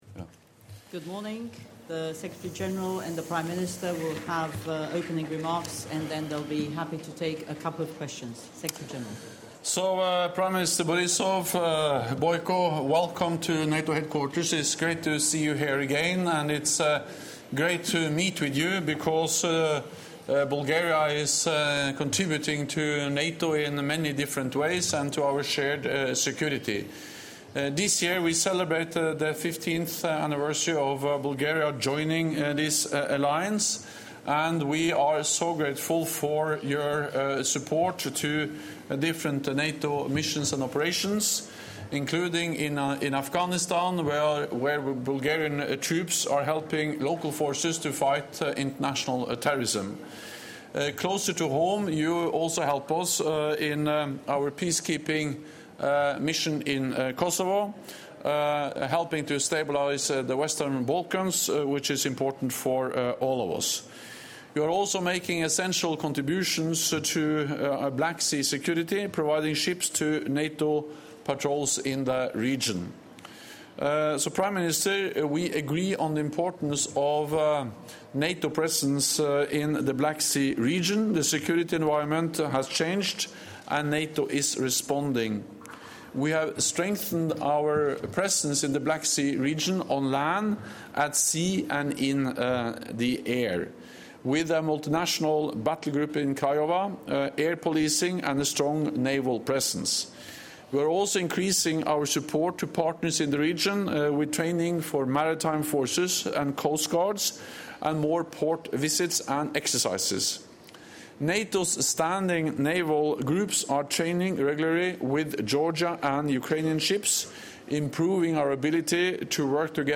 Joint press point